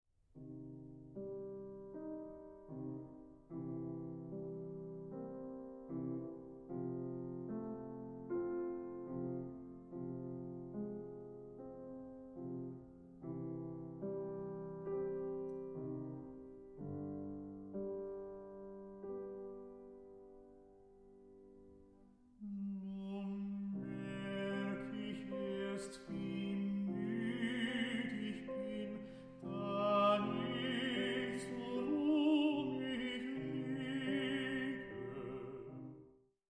Tenor
Piano
This studio recording